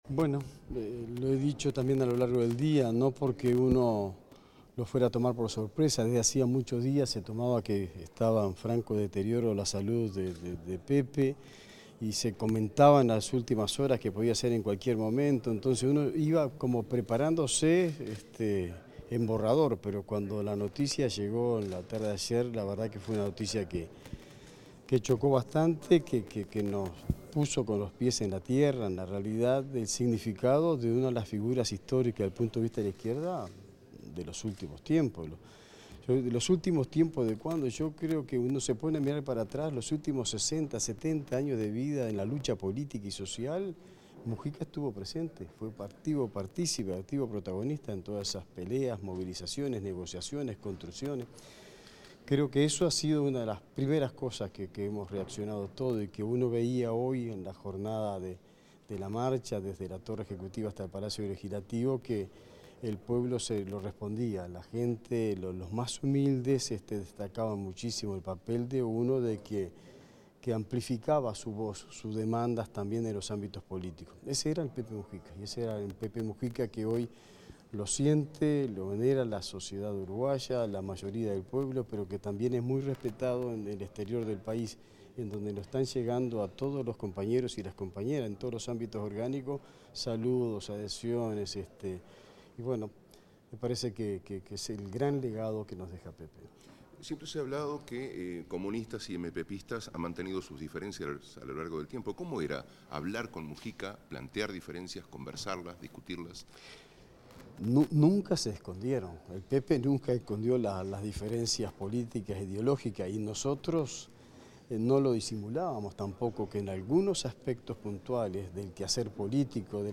Declaraciones del ministro de Trabajo y Seguridad Social, Juan Castillo
El ministro de Trabajo y Seguridad Social, Juan Castillo, dialogó con la prensa en el Palacio Legislativo, acerca de la figura del exmandatario José